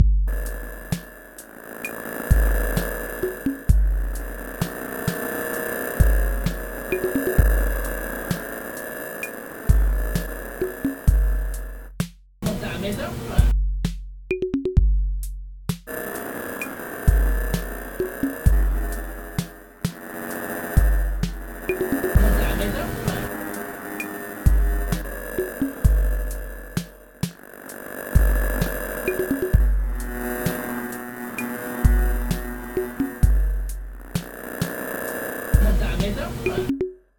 beat1
beat1.mp3